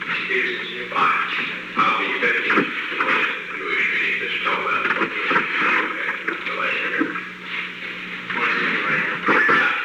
Secret White House Tapes
Conversation No. 902-2
Location: Oval Office
The President met with an unknown man.